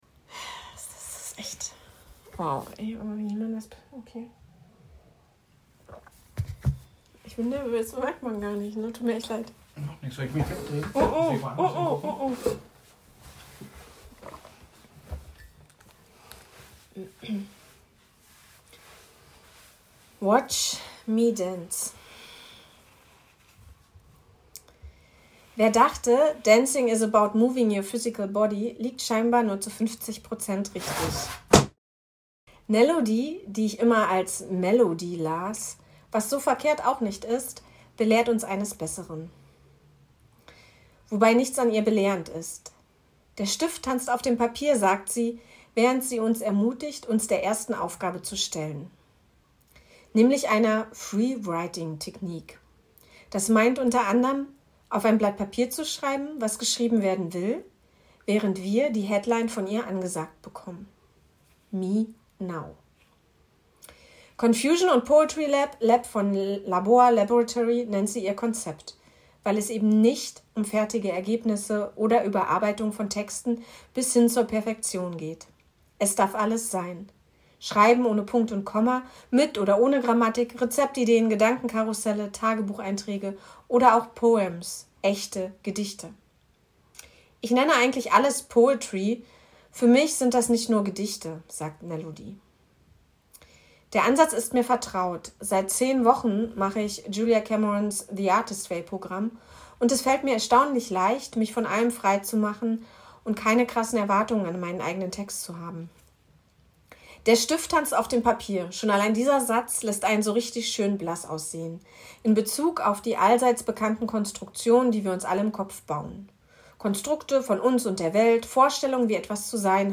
Reading_Watch_me_dance.mp3